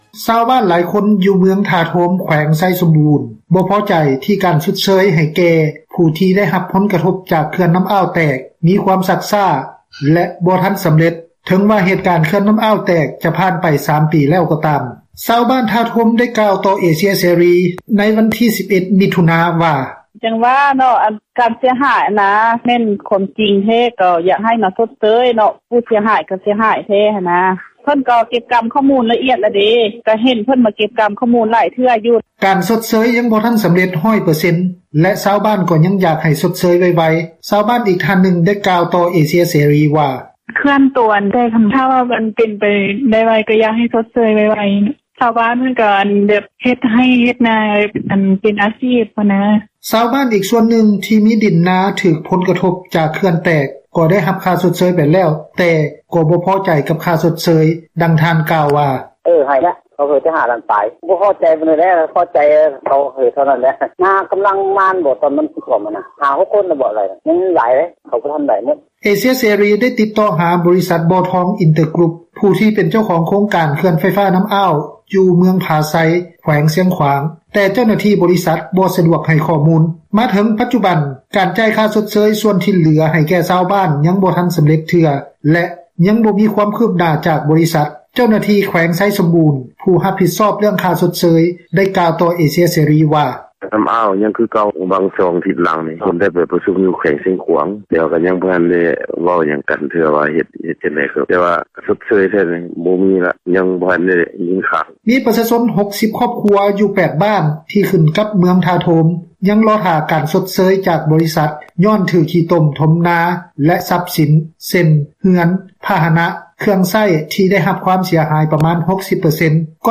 ການຊົດເຊີຍຍັງບໍ່ທັນສຳເຣັດ 100 ເປີເຊັນ, ແລະ ຊາວບ້ານກໍຢາກໃຫ້ຊົດເຊີຍໄວໆ, ຊາວບ້ານອີກທ່ານນຶ່ງ ໄດ້ກ່າວຕໍ່ເອເຊັຽເສຣີ ວ່າ: